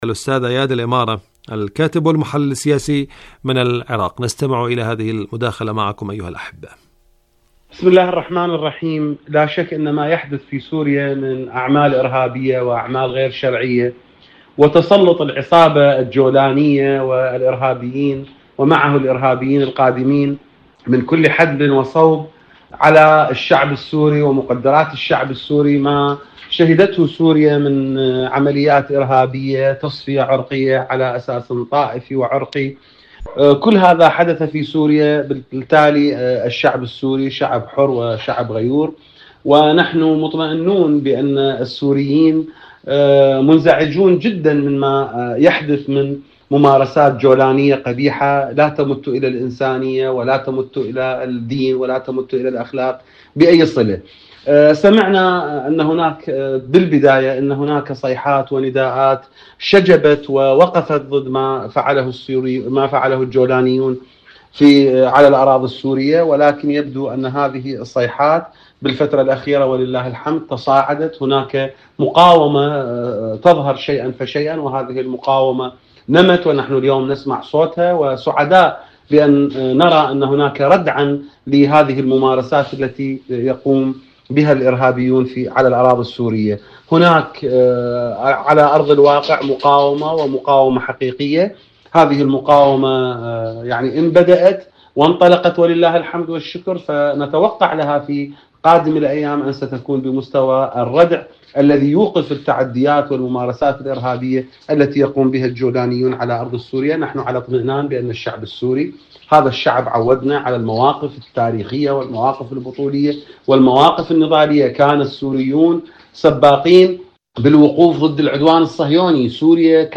تعافي المقاومة في سوريا.. مقابلة